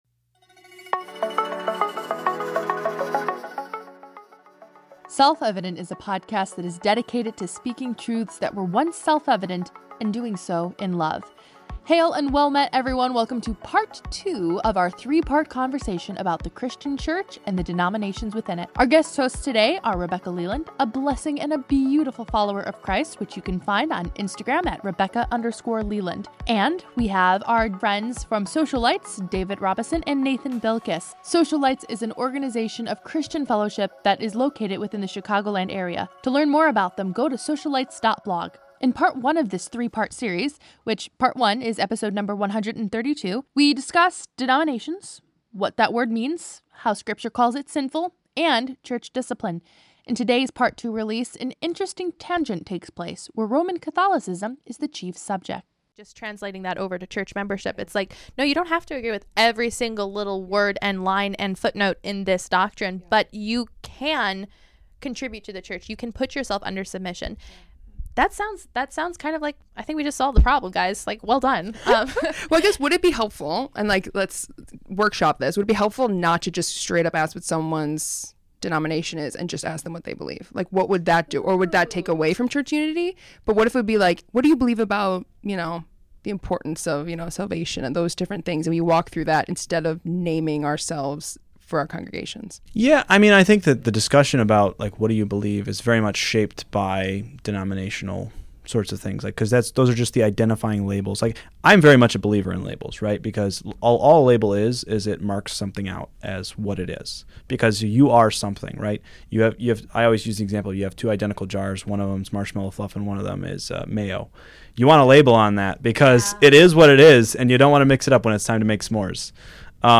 Welcome to part two of our three part conversation about Christian church & the denominations within it.